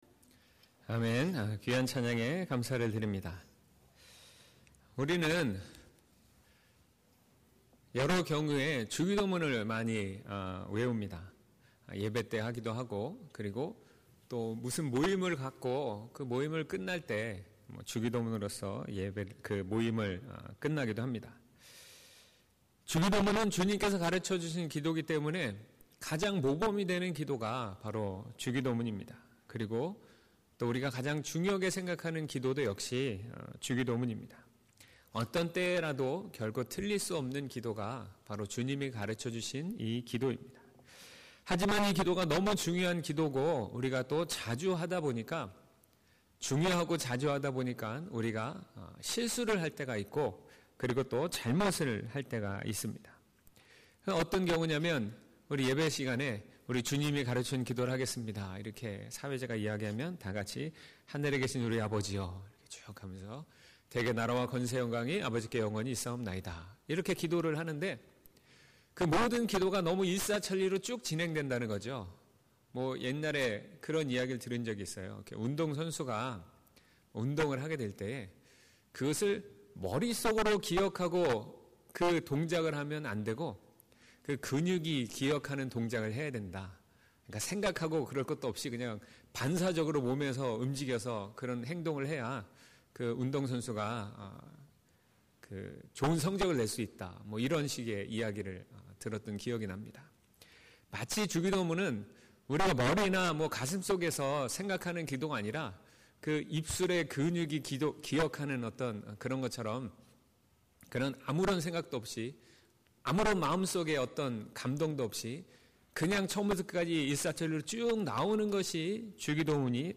1월27일주일설교/주님이 가르쳐주신 기도1/마6:9-13